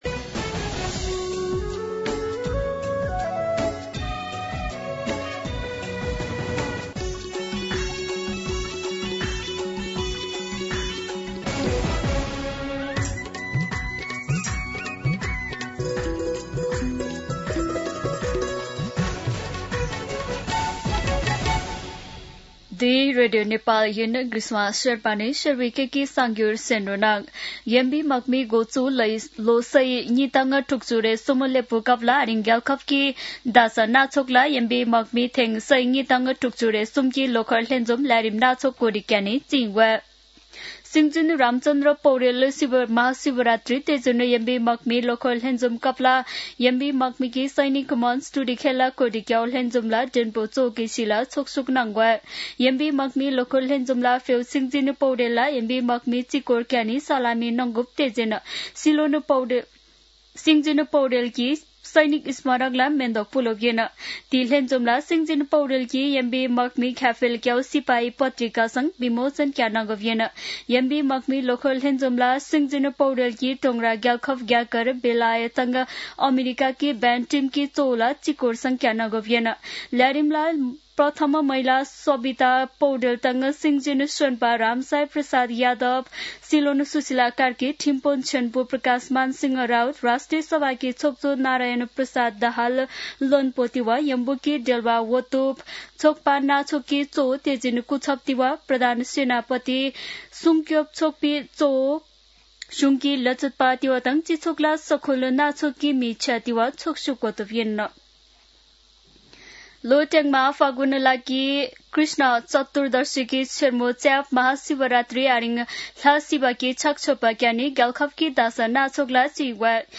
शेर्पा भाषाको समाचार : ३ फागुन , २०८२
Sherpa-News-03.mp3